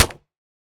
Minecraft Version Minecraft Version latest Latest Release | Latest Snapshot latest / assets / minecraft / sounds / block / mud_bricks / break4.ogg Compare With Compare With Latest Release | Latest Snapshot